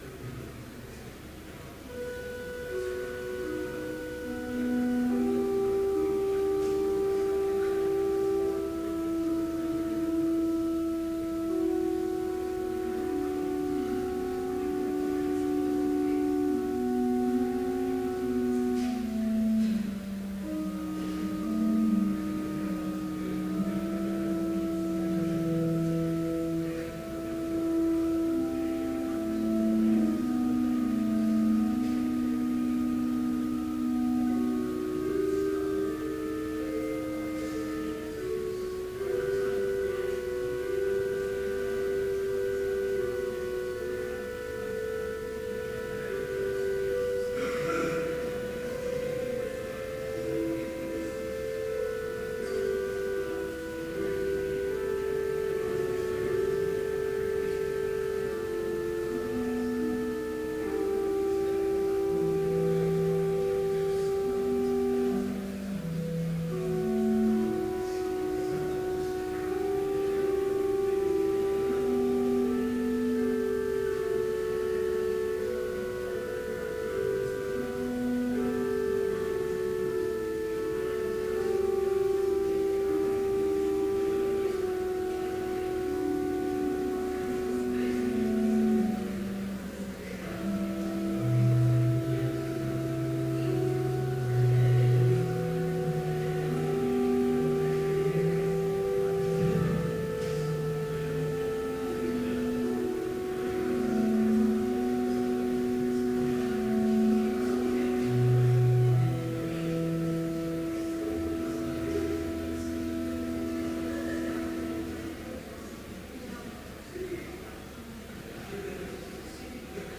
Complete service audio for Chapel - April 10, 2014
Sermon